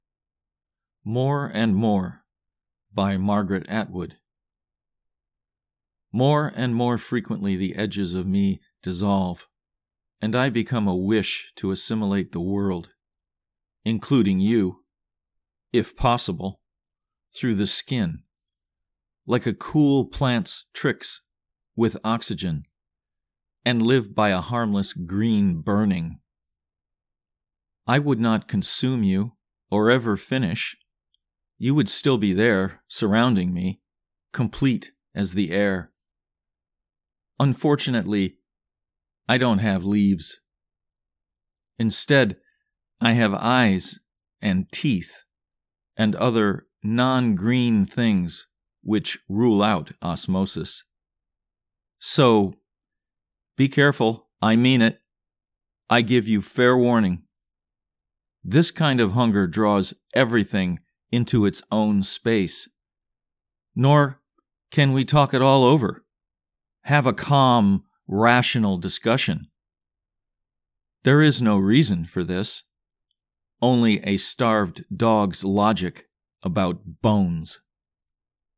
More and More © by Margaret Atwood (Recitation)